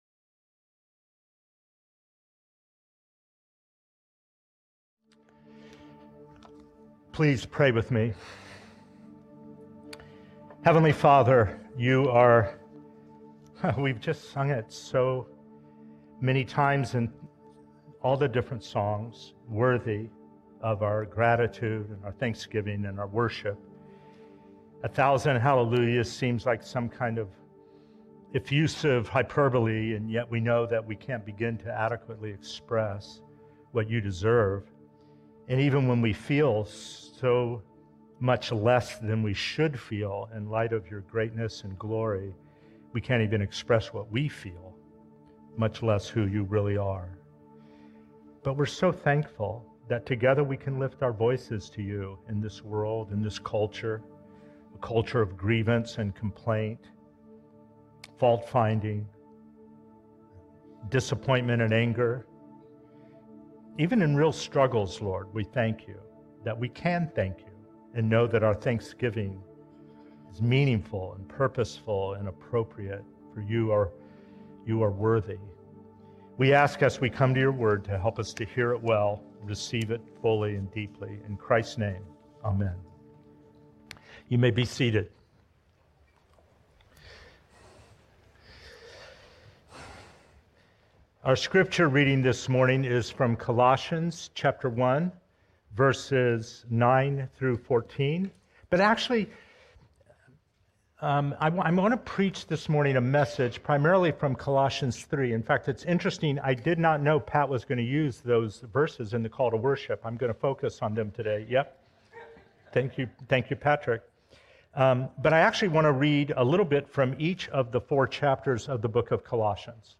1 The End of Time Sermon 38:02